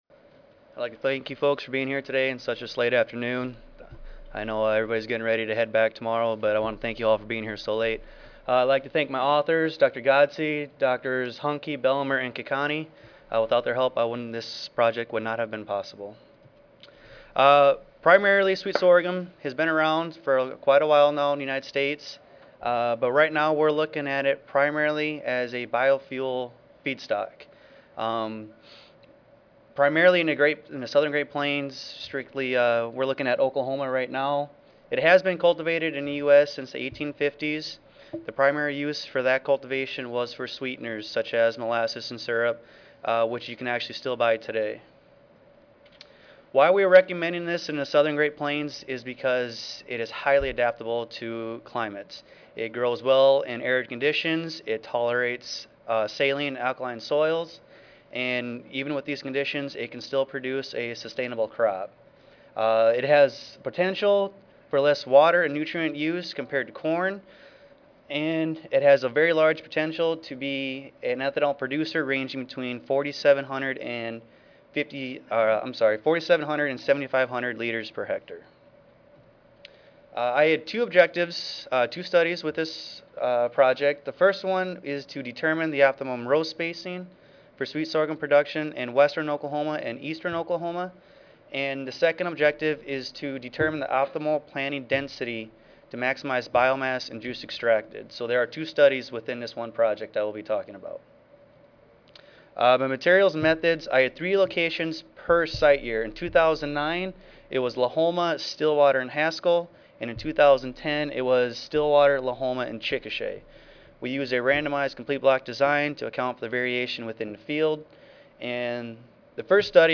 Hyatt Regency Long Beach, Seaview Ballroom C, First Floor
Audio File Recorded presentation